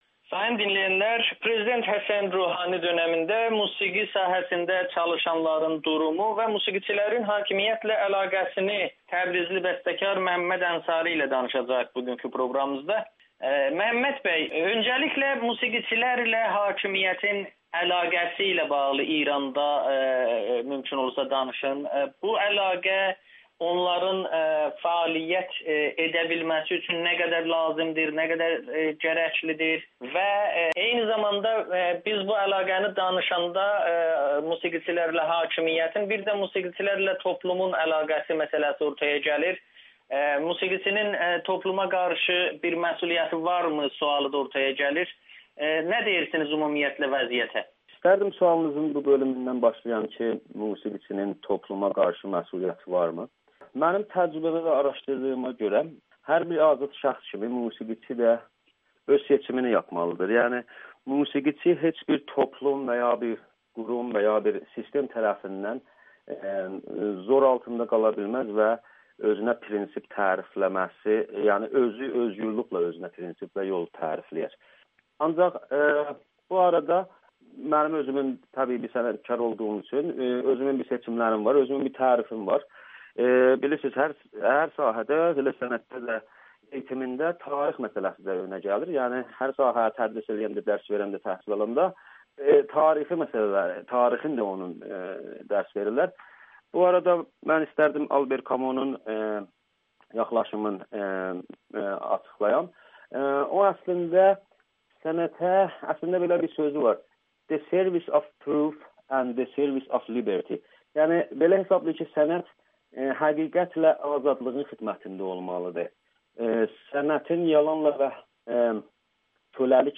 propaqanda vasitəsi kimi baxır [Audio-Müsahibə]